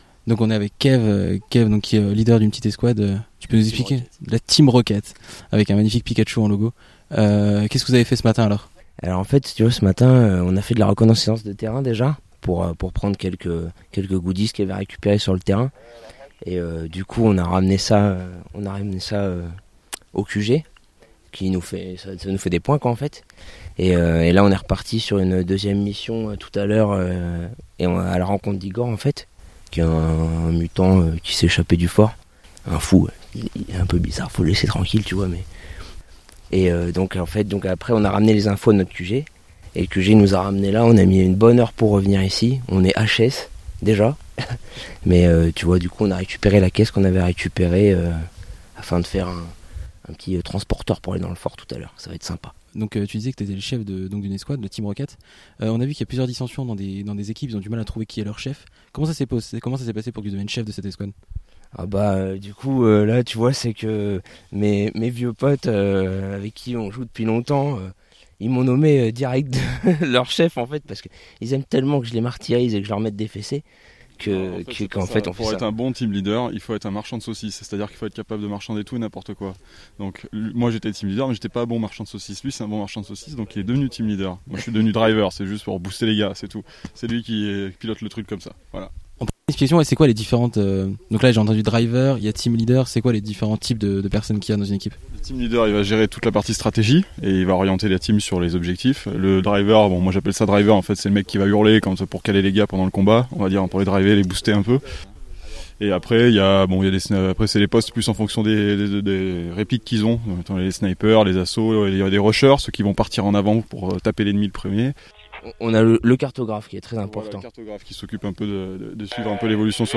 itw-team-leader.mp3